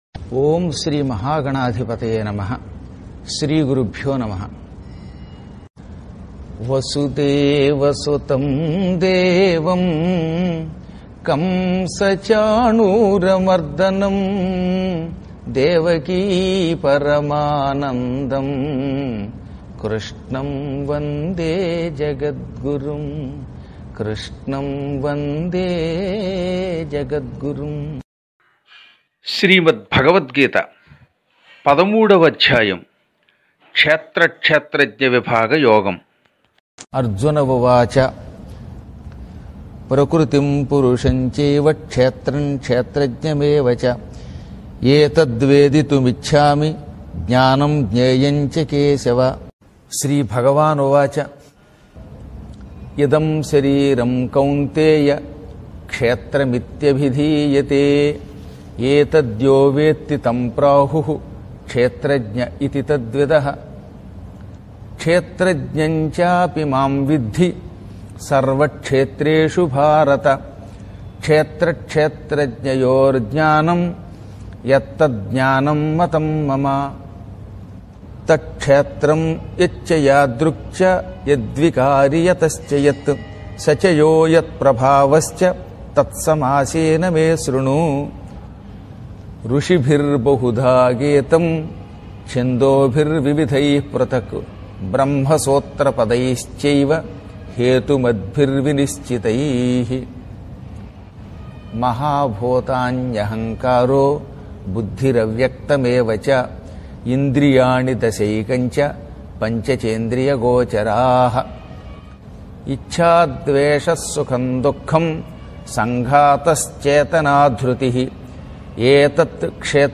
• 13 : 13.శ్రీమద్ భగవద్గీత పఠన శ్లోక పారాయణము|13.Srimad Bhagavadgitha patana sloka parayanamu13 : 13.శ్రీమద్ భగవద్గీత పఠన శ్లోక పారాయణము|13.Srimad Bhagavadgitha patana sloka parayanamu
13-shrimad-bhagavadgita-pathana-shloka-parayanamu13-srimad-bhagavadgitha-patana-sloka-parayanamu.mp3